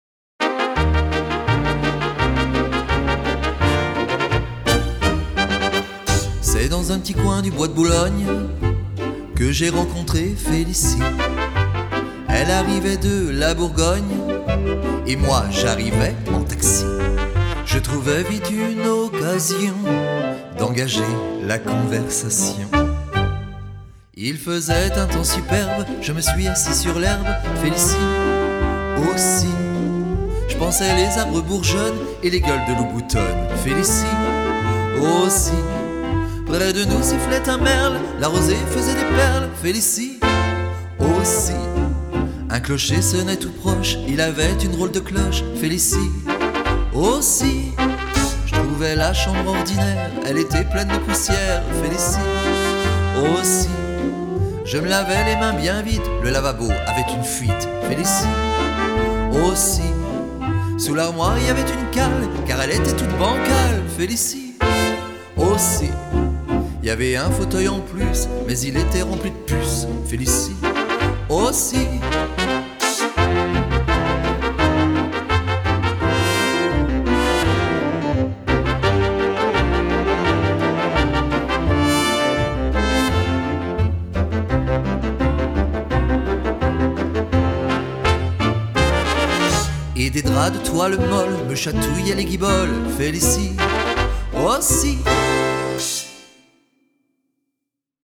La version chantée